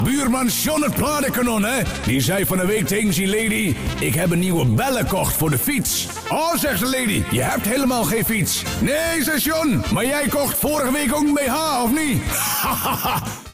Play Mop Fietsbel - SoundBoardGuy
mop-fietsbel-bh.mp3